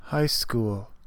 Ääntäminen
IPA : /haɪ skuːl/